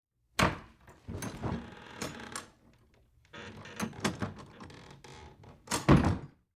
Creak, Door
Heavy Wood Door Open And Close With Metal Latch And Creaks, X2